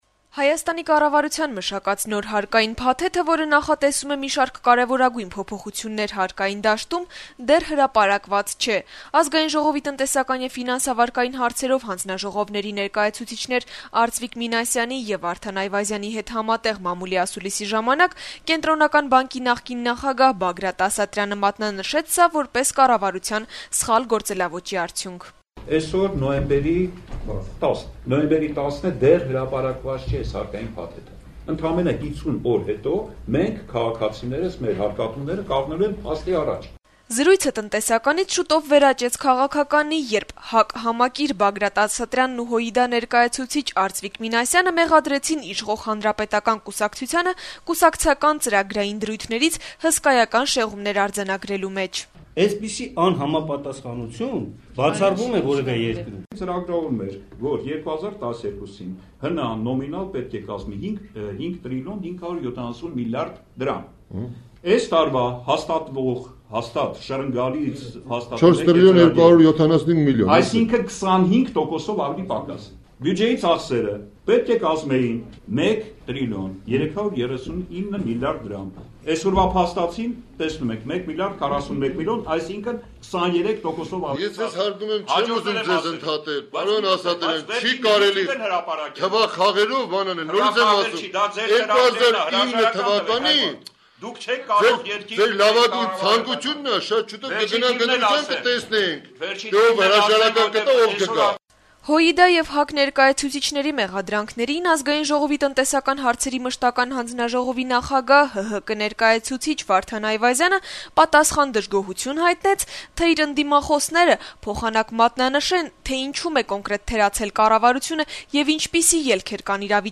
Ազգային ժողովի Տնտեսական եւ ֆինանսավարկային հարցերով հանձնաժողովների ներկայացուցիչներ Արծվիկ Մինասյանի (ՀՅԴ) եւ Վարդան Այվազյանի (ՀՀԿ) հետ համատեղ մամուլի ասուլիսի ժամանակ, Կենտրոնական բանկի նախկին նախագահ Բագրատ Ասատրյանը մատնանշեց սա` որպես կառավարության սխալ գործելաոճի արդյունք: